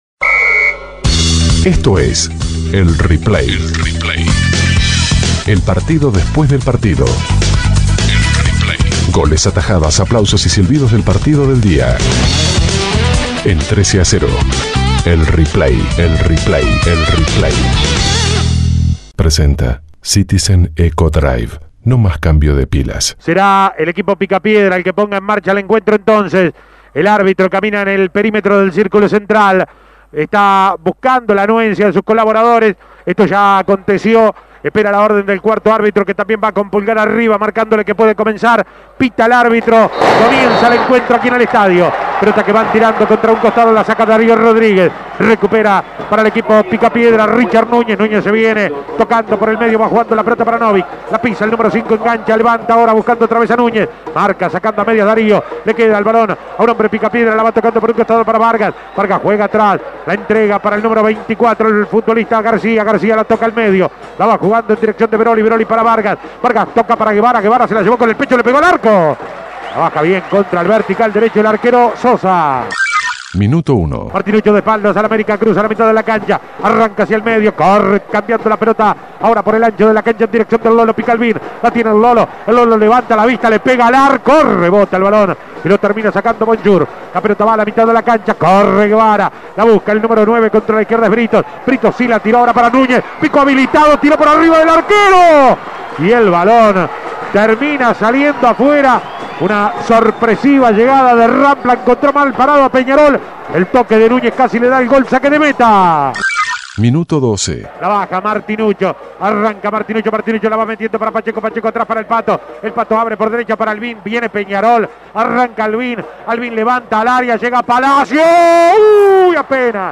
Goles y comentarios Escuche el replay de Peñarol - Rampla Imprimir A- A A+ Peñarol empató 1 a 1 con Rampla Juniors por la fecha 11 del Torneo Apertura.